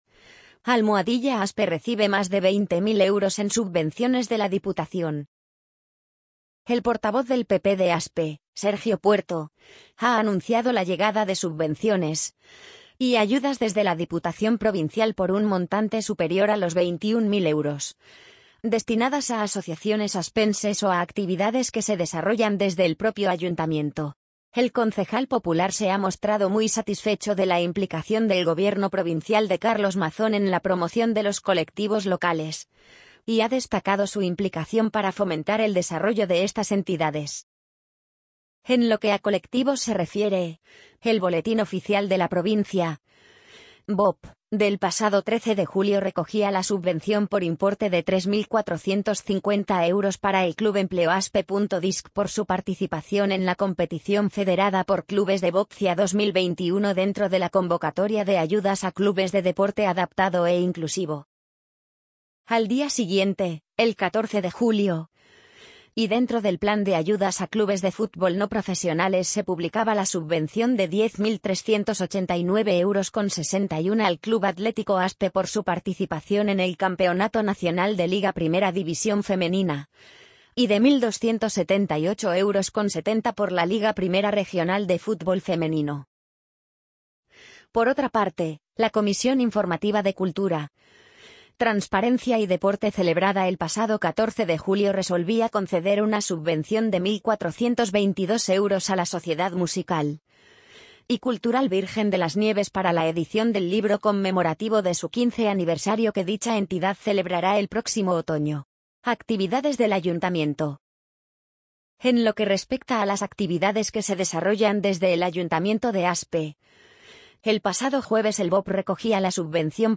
amazon_polly_50770.mp3